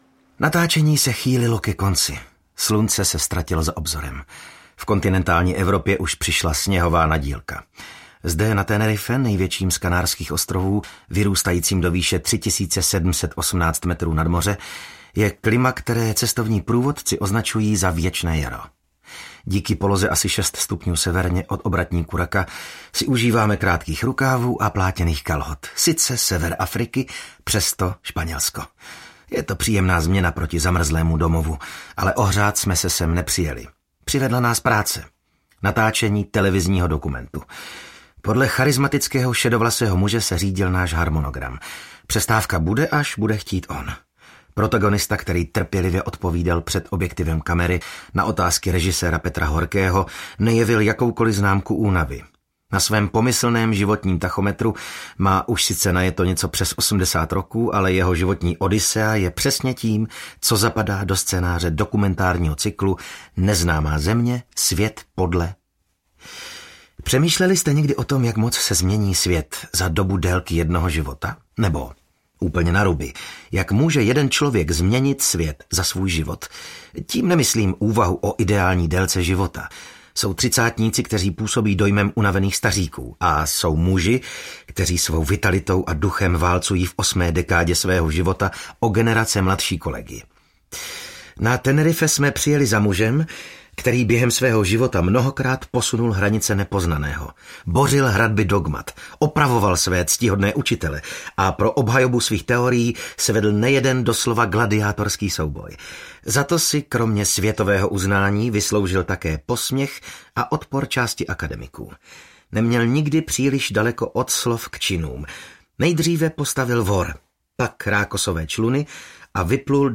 Plavby "sebevrahů" audiokniha
Ukázka z knihy